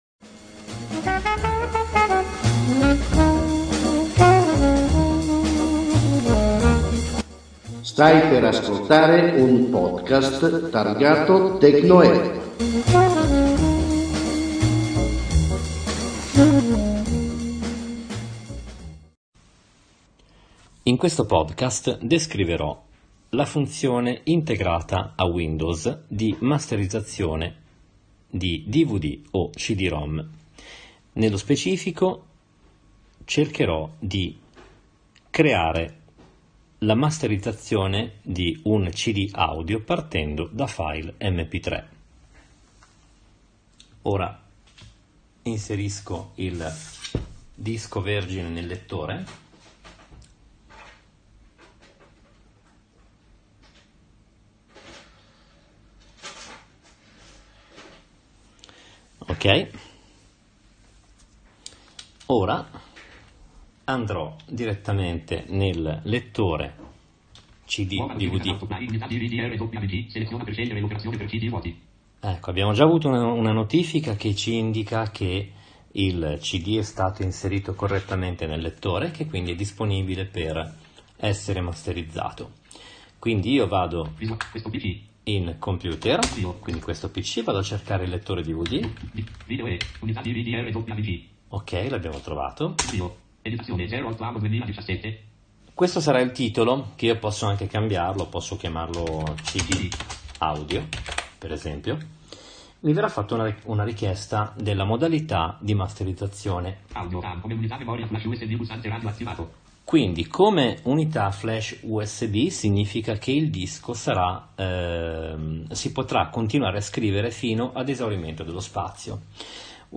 con lo screenreader Jaws